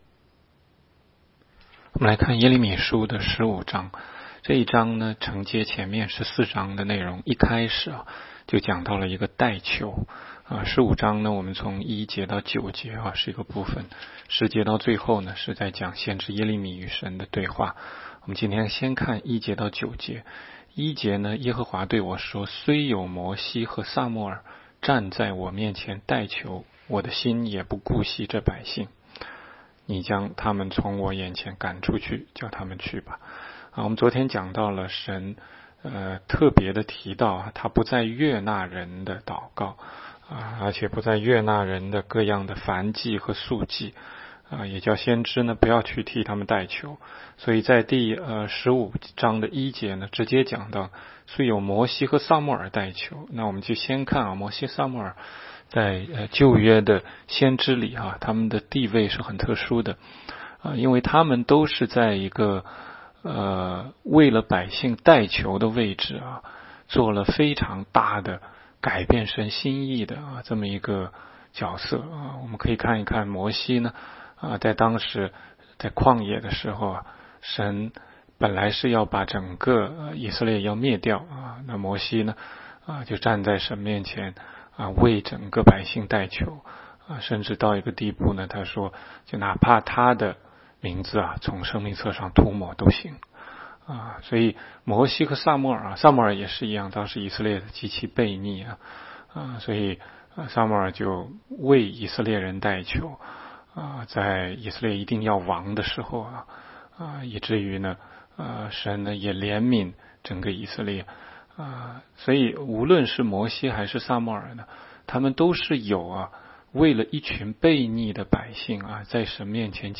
16街讲道录音 - 每日读经 -《耶利米书》15章